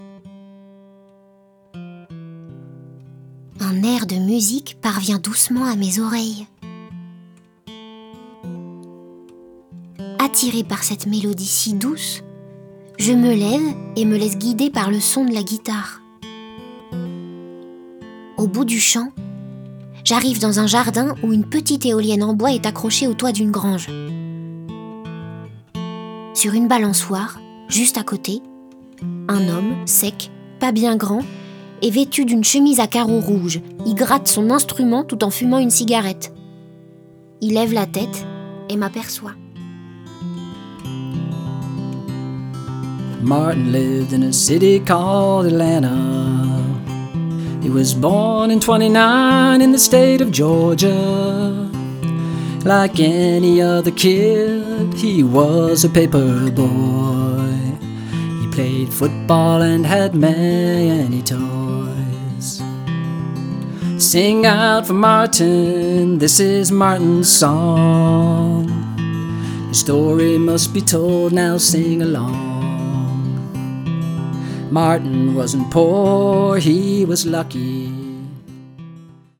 Get £1.29 by recommending this book 🛈 Fiction sonore. Un voyage en musique folk (banjo, guitare, contrebasse) entre le New-York ségrégationniste des années 1930 et le Paris d’aujourd’hui.